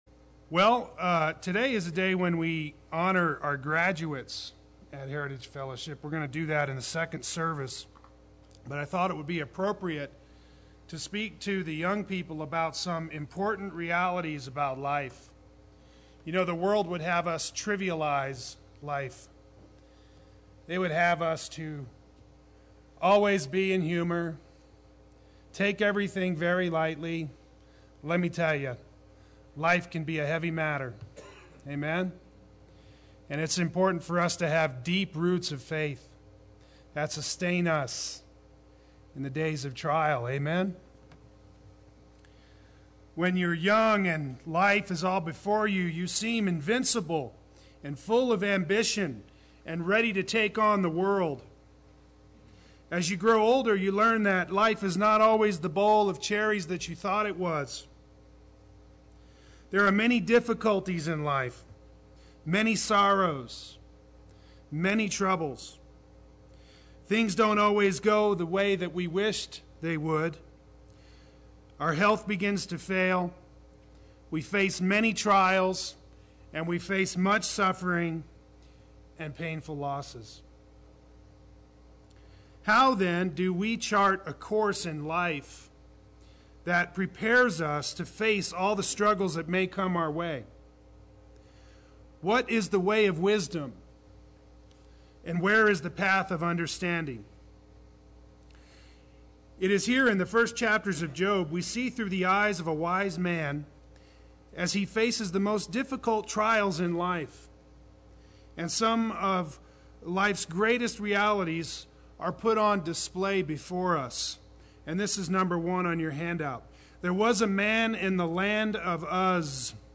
Play Sermon Get HCF Teaching Automatically.
Lessons for the Young and Old from Job Sunday Worship